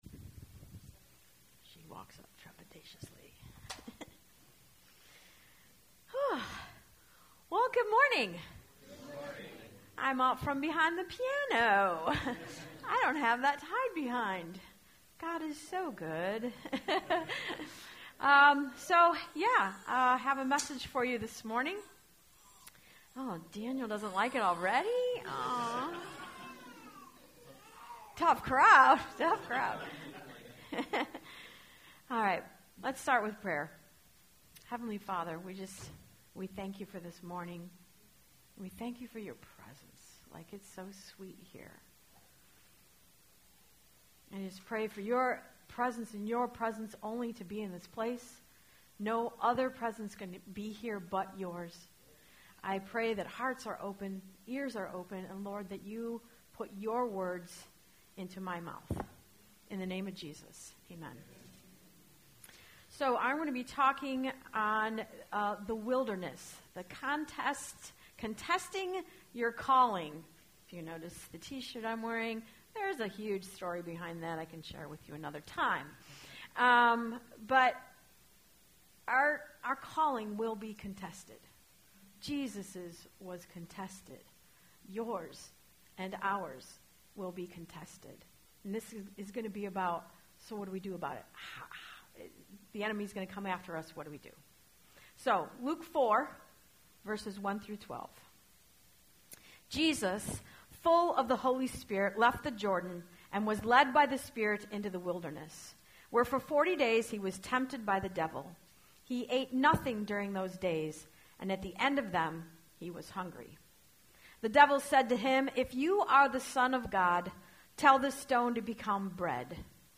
Live preaching
sermon